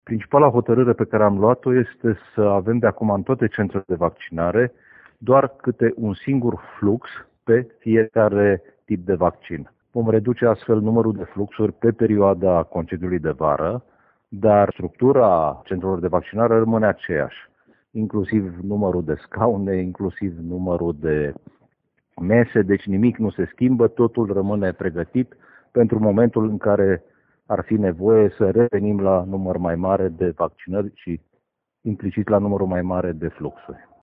Decizia a fost luată de Nucleul Județean de Vaccinare, spune subprefectul Ovidiu Drăgănescu: